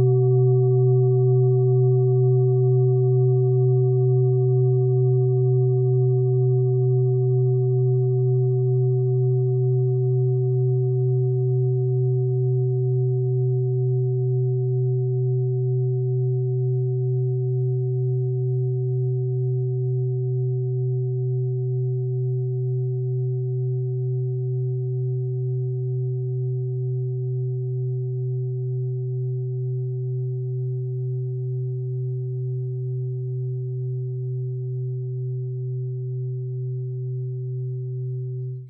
Klangschale Bengalen Nr.31
Sie ist neu und wurde gezielt nach altem 7-Metalle-Rezept in Handarbeit gezogen und gehämmert.
Die 24. Oktave dieser Frequenz liegt bei 187,61 Hz. In unserer Tonleiter liegt dieser Ton nahe beim "Fis".
klangschale-ladakh-31.wav